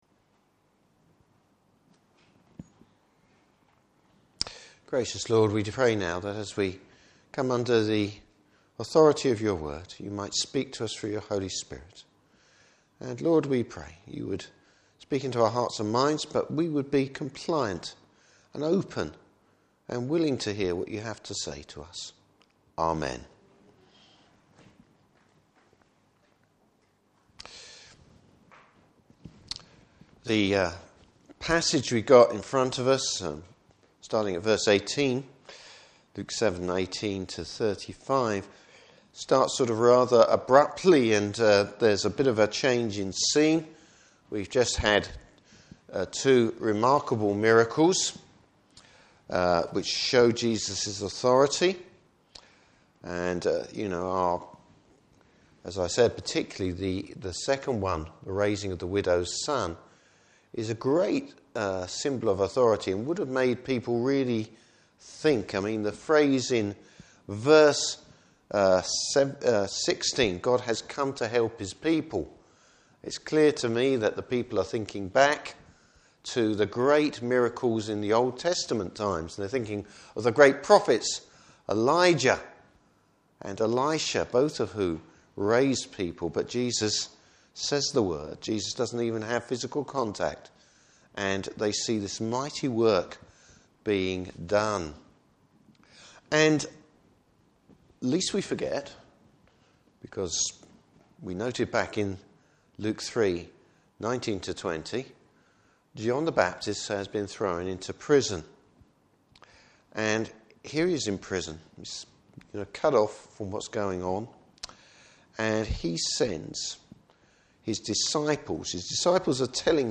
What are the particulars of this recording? Service Type: Morning Service Bible Text: Luke 7:18-35.